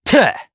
Neraxis-Vox_Landing_jp.wav